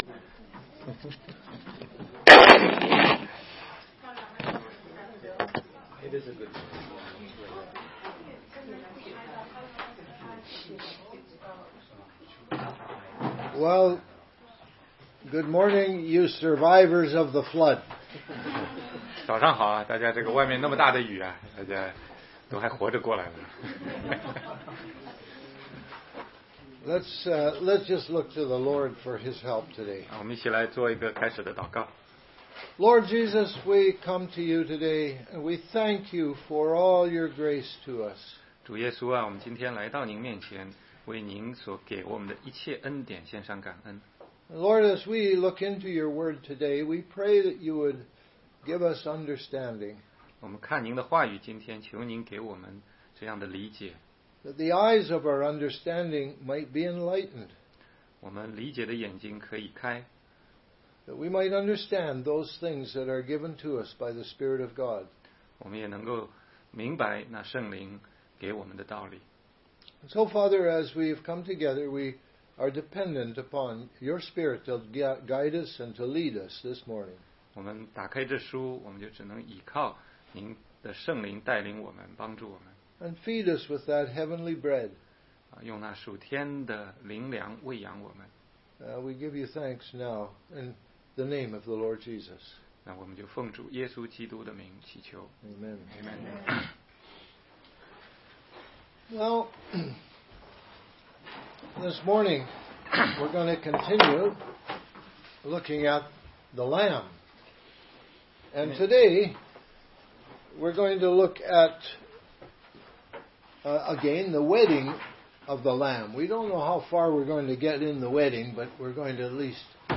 16街讲道录音 - 怎样才能读懂圣经系列之十六